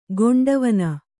♪ goṇḍa vana